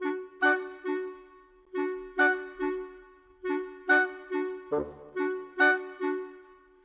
Modern 26 Winds 01.wav